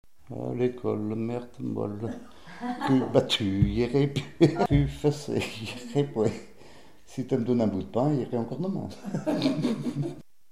Mémoires et Patrimoines vivants - RaddO est une base de données d'archives iconographiques et sonores.
Thème : 0080 - L'enfance - Enfantines diverses
Catégorie Pièce musicale inédite